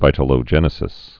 (vītl-ō-jĕnĭ-sĭs, vĭt-)